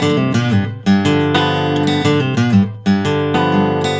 Index of /90_sSampleCDs/ILIO - Fretworks - Blues Guitar Samples/Partition G/120BARI RIFF